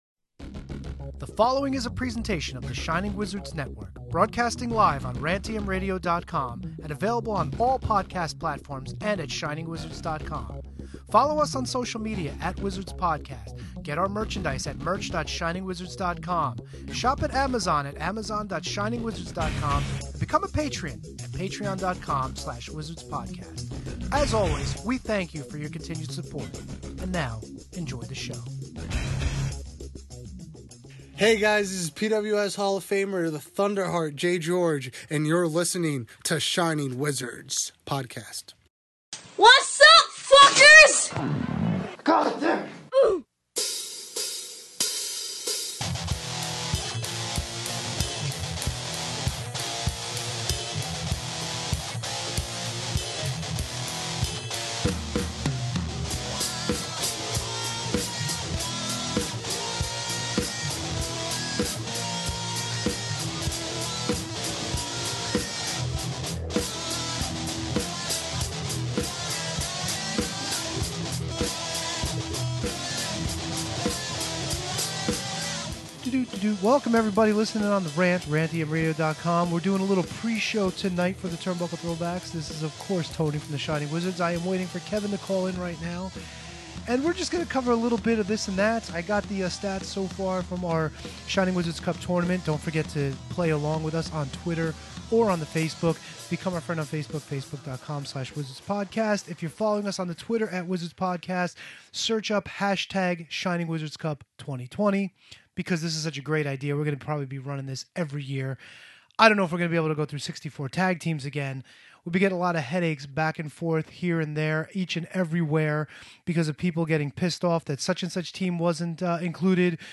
on Skype with coverage of the second round of the Shining Wizards cup, the Dark Side of the Ring episodes on Chris Benoit, and some breaking changes to WrestleMania.